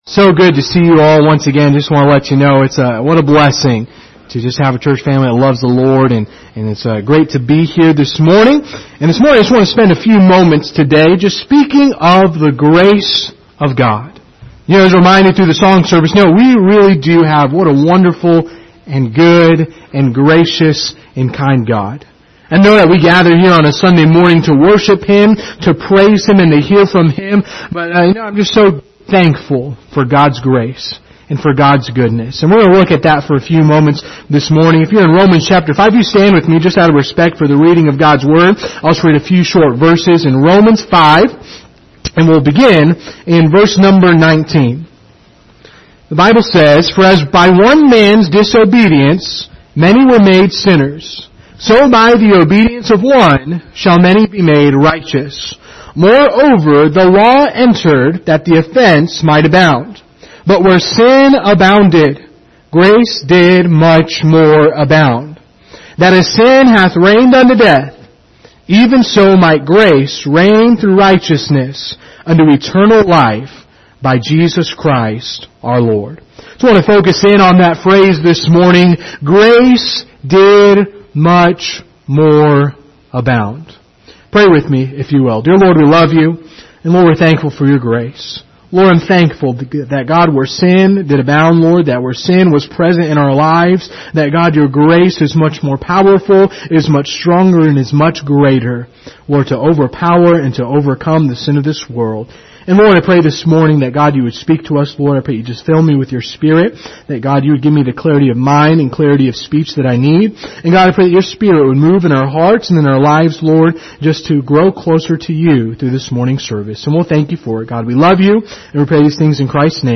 Passage: Romans 5:19-21 Service Type: Sunday Morning Topics